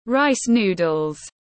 Bún tiếng anh gọi là rice noodles, phiên âm tiếng anh đọc là /raɪs nuː.dəl/
Rice noodles /raɪs nuː.dəl/